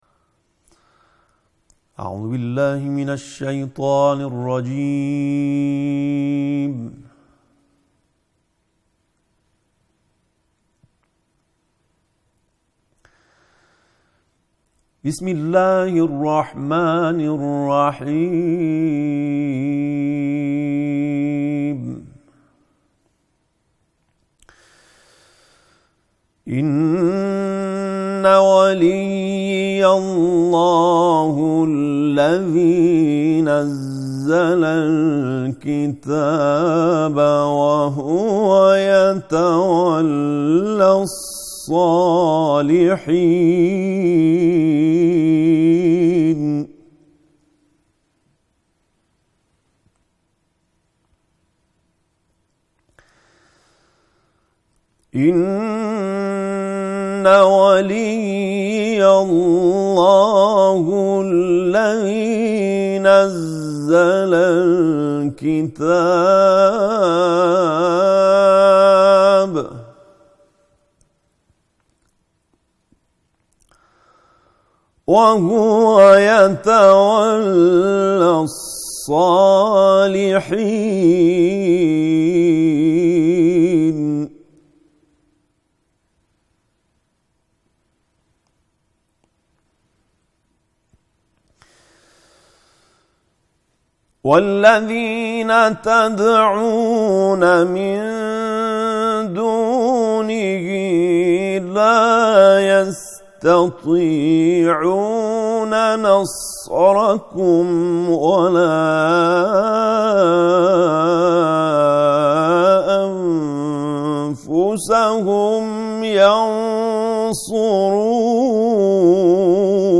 سیزدهمین محفل با تلاوت